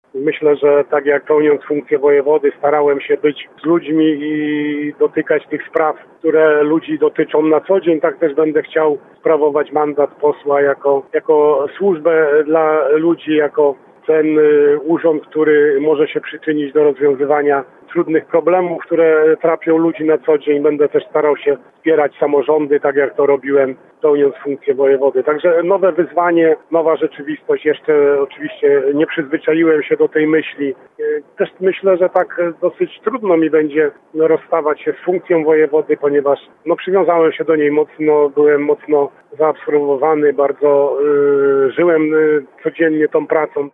– Mój wynik to ogromne zobowiązanie – mówi Wojewoda Lubuski Władysław Dajczak, który zasiądzie w poselskich ławach PiS: